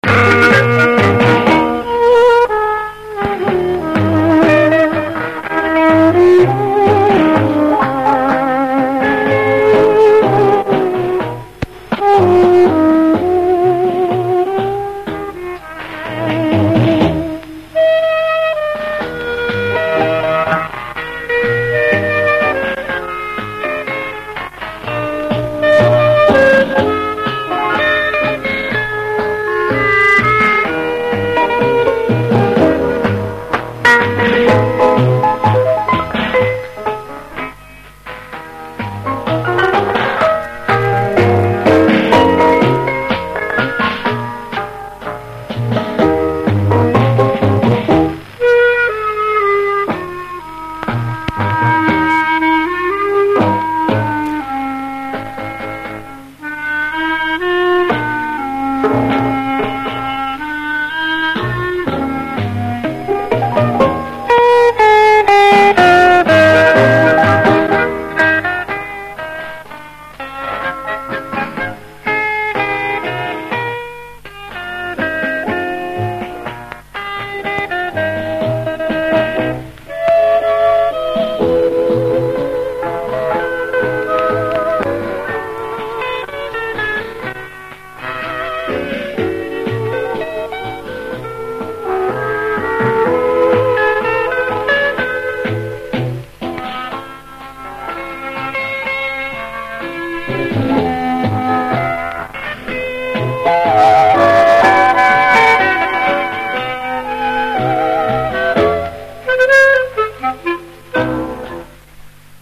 Танго "O Sole Mio".
Вполне возможно что это тот же самый оркестр, который исполняет румбу "Продавец орехов" в версии, которую я выкладывал ранее
tango-o-sole-mio-(zapis-60-h-godov).mp3